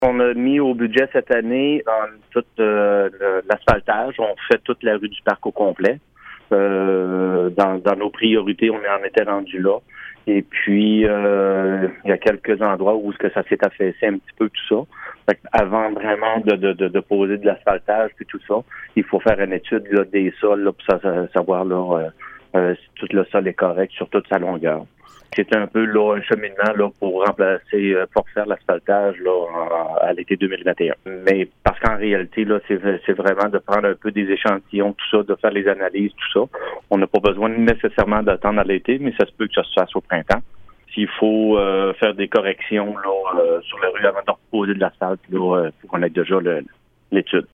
Le maire suppléant de Fermont, Marco Ouellet, a donné davantage de détails en entrevue à CFMF 103,1 :